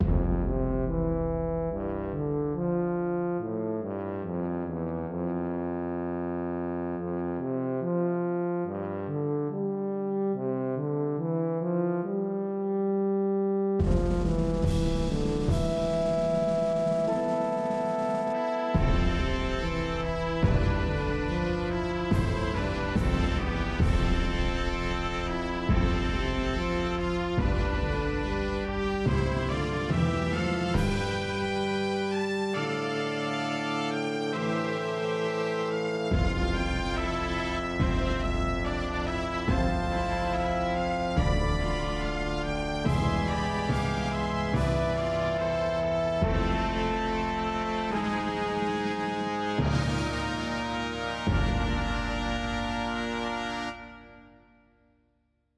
I arranged one of my short piano ideas into a short (I do not know where to take it past the 1 minute mark) orchestral piece The MIDI I uploaded is a little messed up since I used an FL studio sound pack so in some places it shows notes where there's actually drums orchestra.mid orchestra.mid